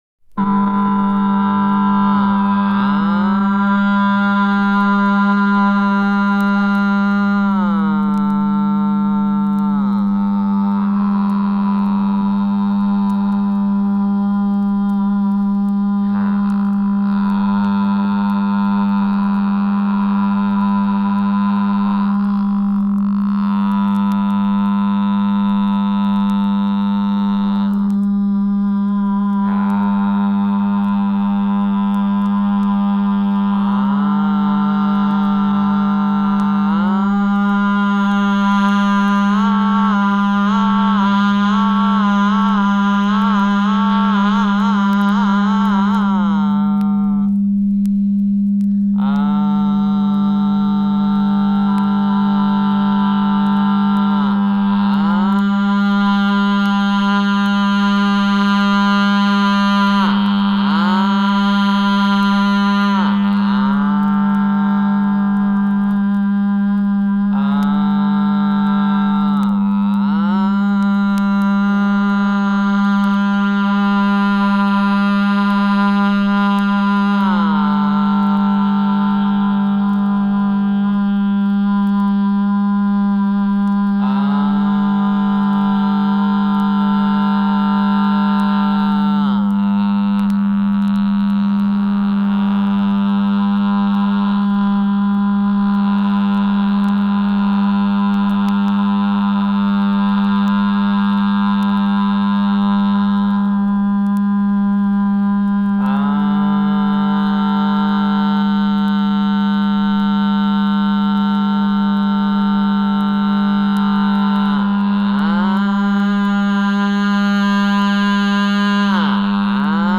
The father of drone, the man himself.